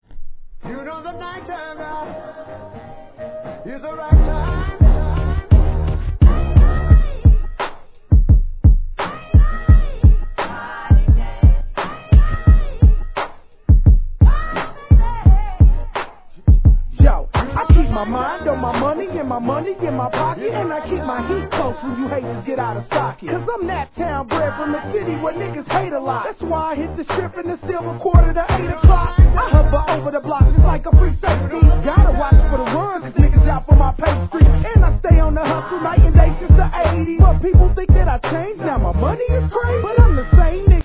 G-RAP/WEST COAST/SOUTH
実に陽気な作品です